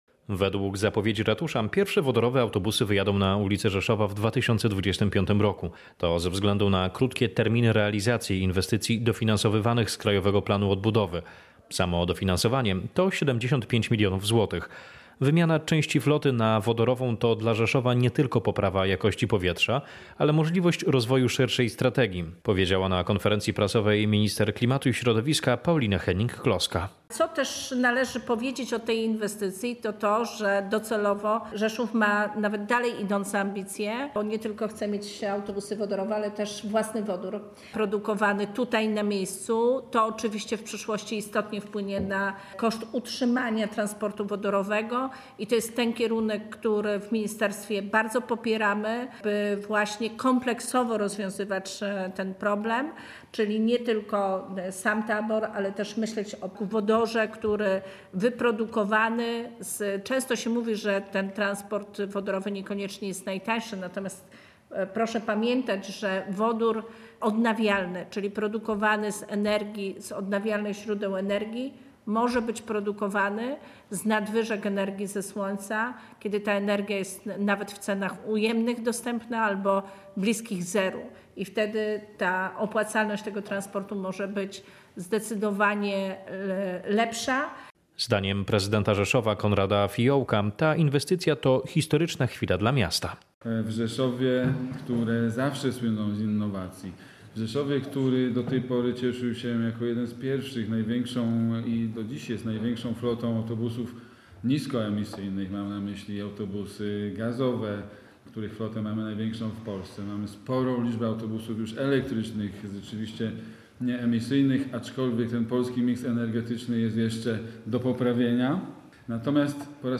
Rzeszów jest prymusem jeśli chodzi o transformację energetyczną – powiedziała na konferencji prasowej w miejskim ratuszu Minister Klimatu i Środowiska Paulina Henning-Kloska.
Relacja